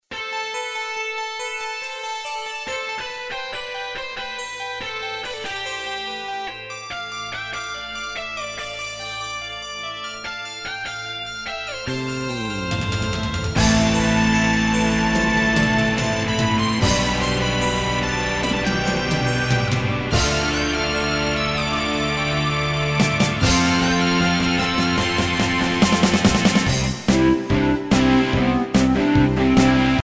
MP3 DEMO,